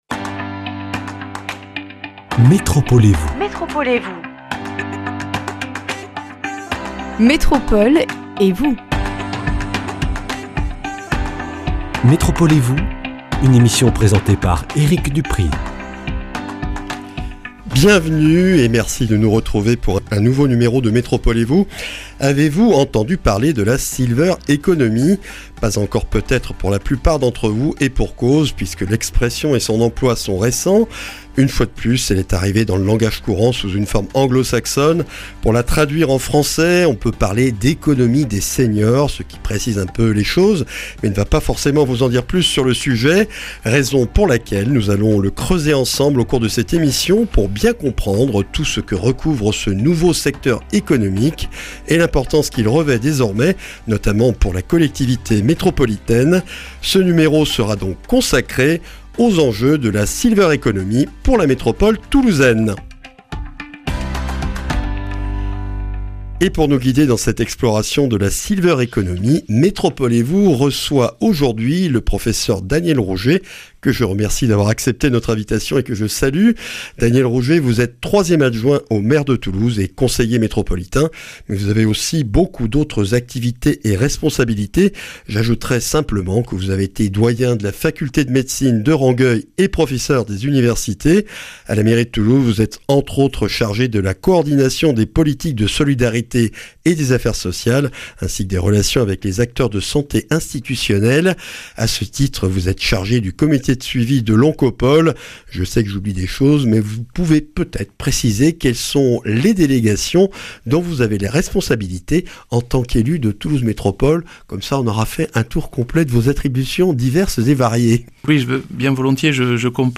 Un numéro dédié à la découverte de l’Économie des seniors avec le professeur Daniel Rougé, 3ème adjoint au maire de Toulouse et conseiller métropolitain. Ce qu’on appelle le secteur de la Silver Économie prend de plus en plus d’importance dans nos sociétés occidentales mais qu’en est-il dans la métropole toulousaine ?